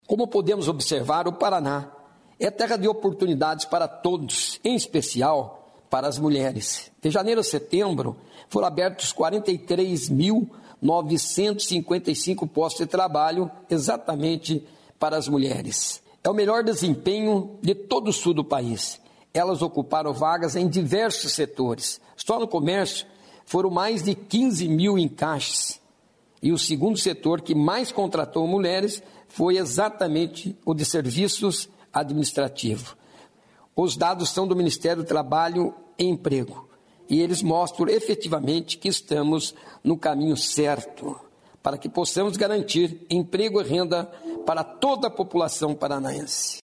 Sonora do secretário de Estado do Trabalho, Qualificação e Renda, Mauro Moraes, sobre a empregabilidade feminina no Estado